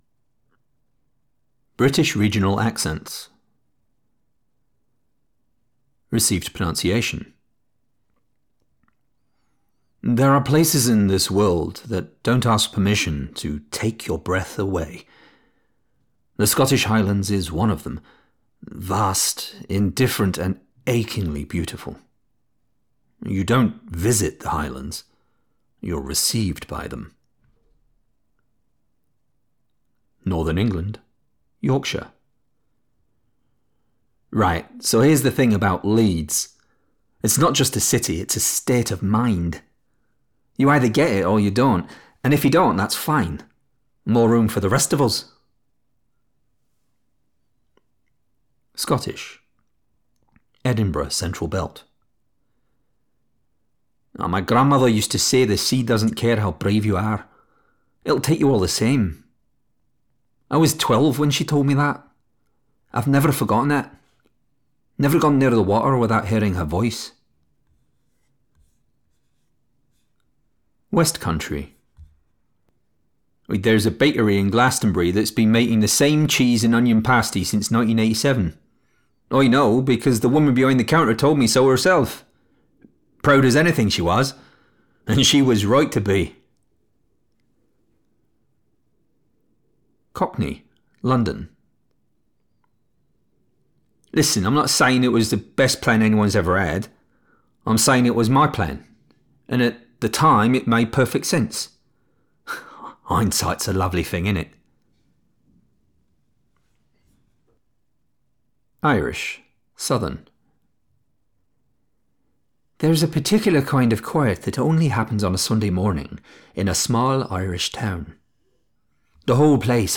British Regional Accents
Middle Aged
DEMO 1 — British Regional Accents.mp3